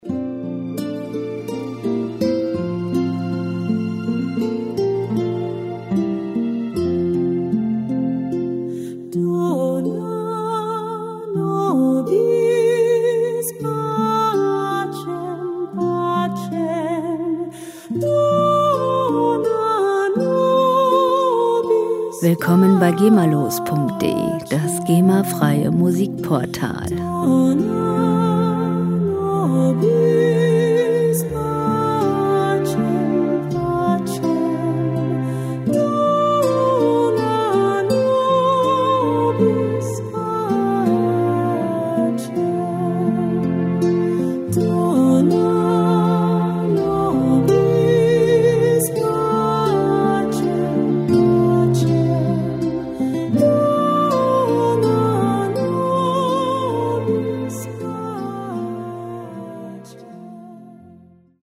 Musikstil: Crossover Klassik
Tempo: 84 bpm
Tonart: F-Dur
Charakter: zart, harmonisch